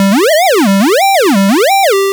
retro_synth_wobble_08.wav